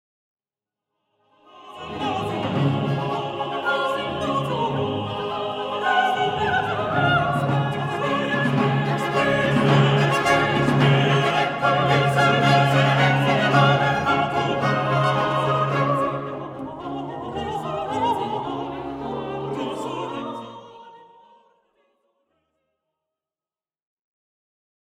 Version pour ensemble de solistes